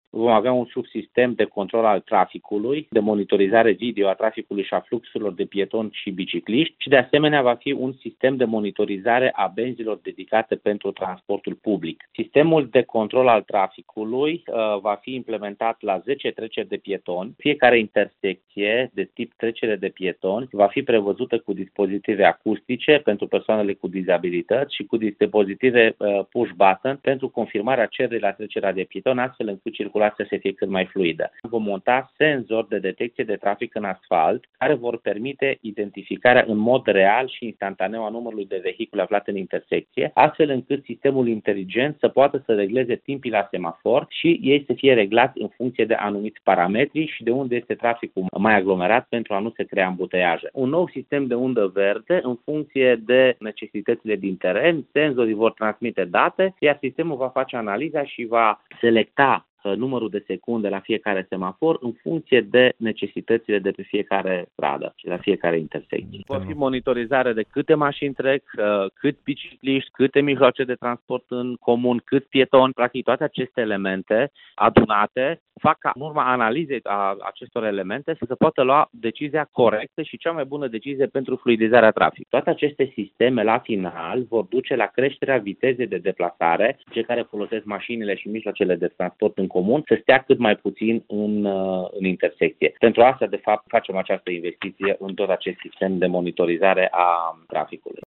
Scopul investițiilor de tip smart în controlul traficului rutier este de creștere a vitezei de deplasare și fluidizare a traficului, a mai spus viceprimarul Dan Tarcea, în declarația de mai jos, în care vorbește și de dotarea semafoarelor cu facilități pentru o deplasarea în siguranță a pietonilor, inclusiv persoane cu dizabilități.